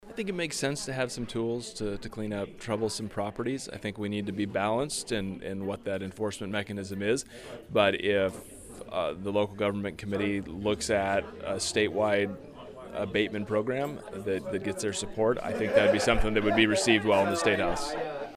51st District State Representative Kenny Titus gave some feedback on the discussion.